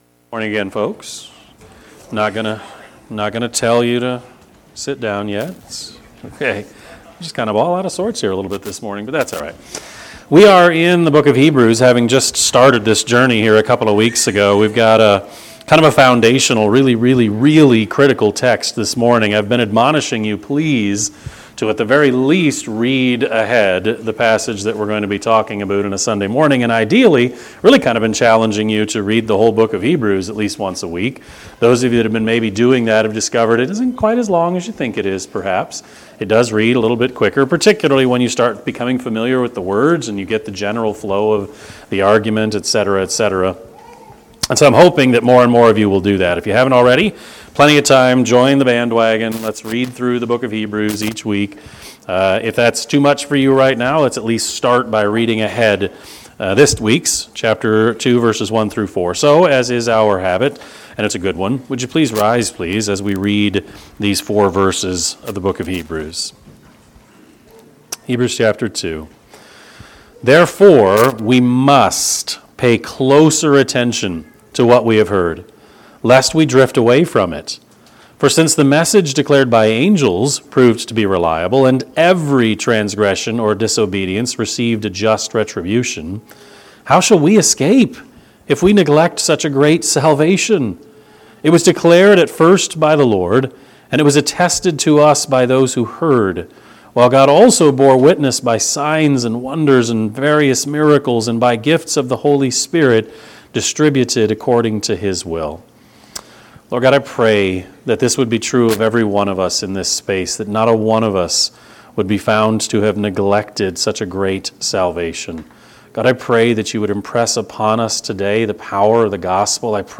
Sermon-6-8-25-Edit.mp3